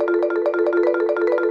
bp_counter_loop_01.ogg